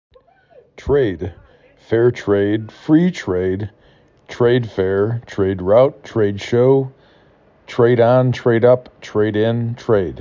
5 Letters, 1 Syllable
t r A d